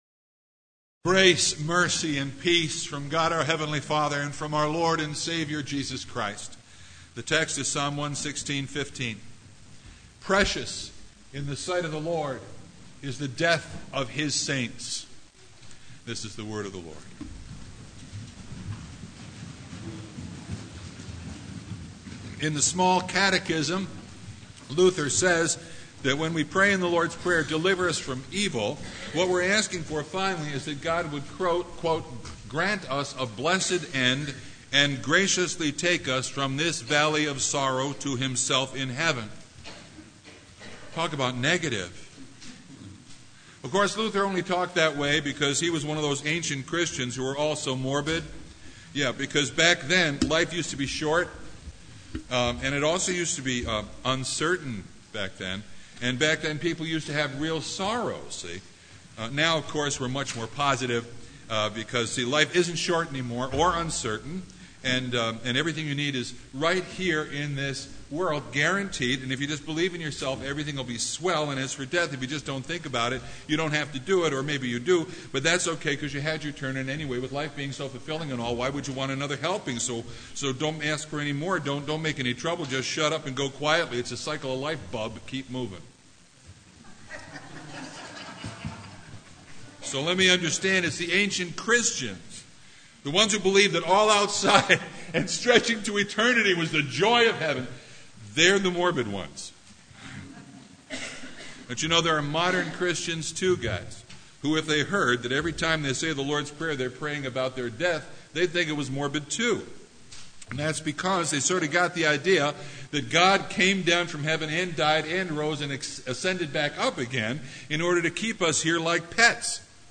Passage: Psalm 116:15 Service Type: Sunday
Sermon Only